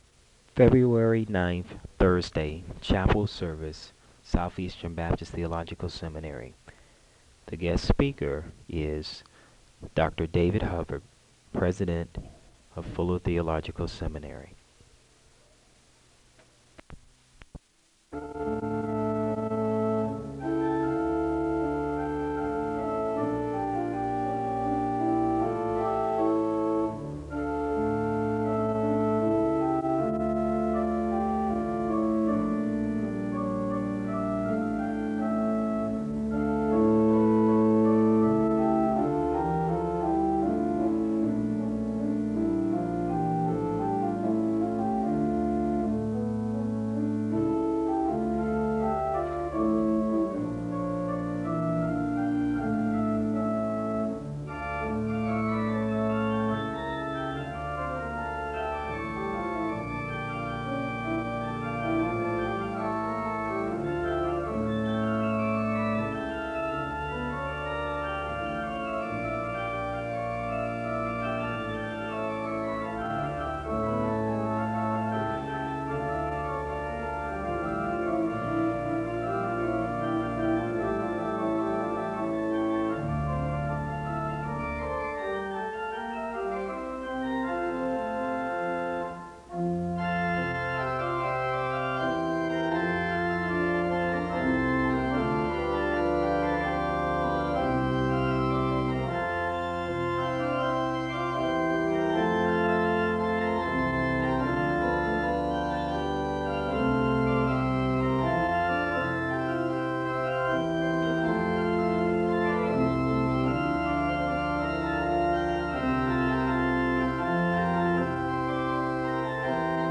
A hymn is played (0:17-4:57). A word of prayer is given (4:58-5:27). A hymn is played (cut) (5:28-5:43).
The choir sings an anthem (7:41-10:16).